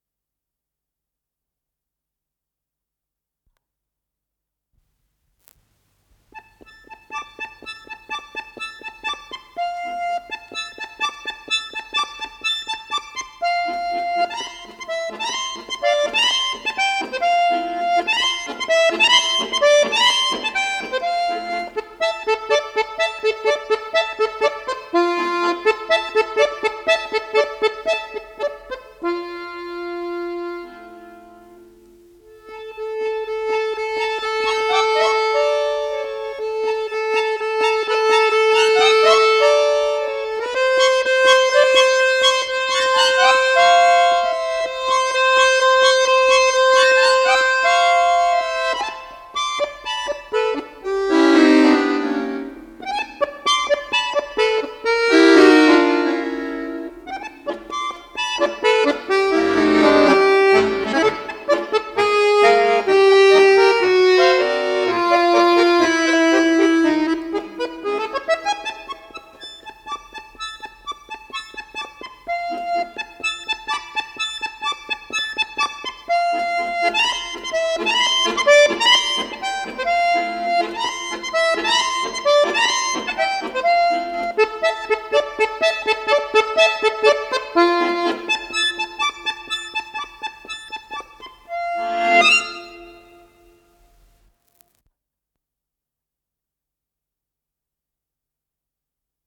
баян